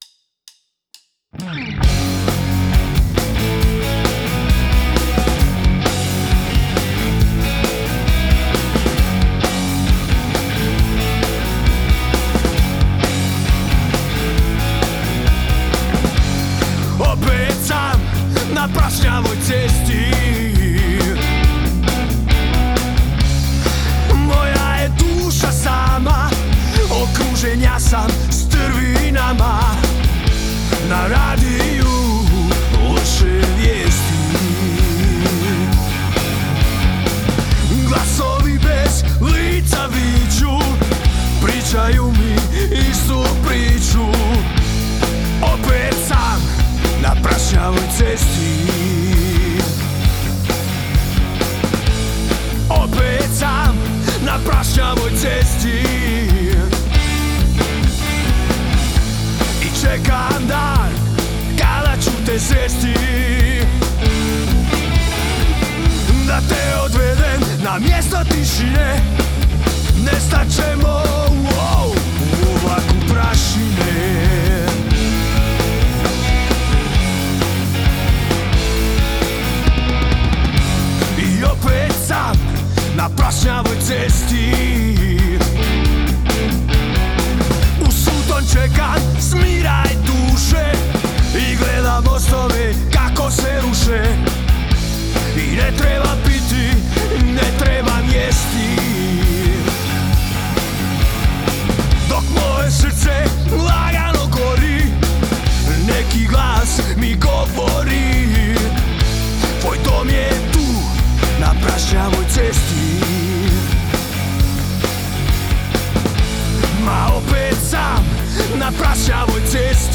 snimljenog i miksanog u studiju